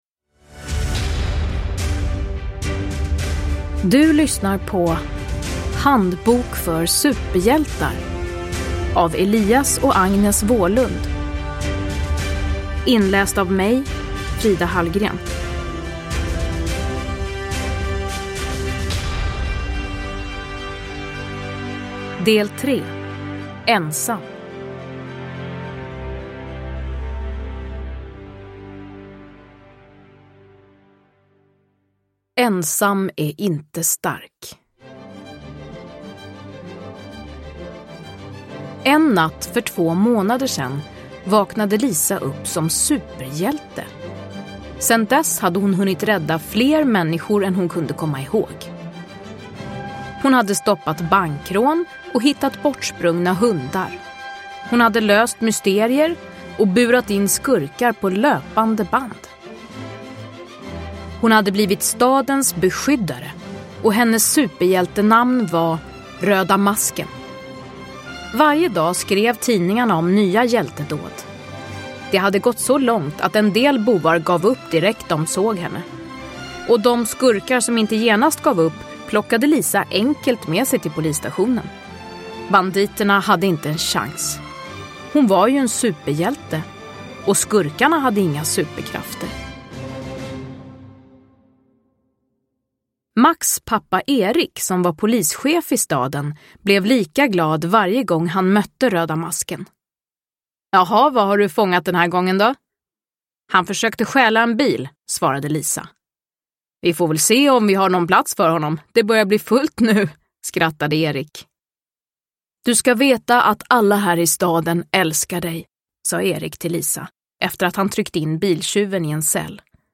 Handbok för superhjältar. Ensam – Ljudbok – Laddas ner
Uppläsare: Frida Hallgren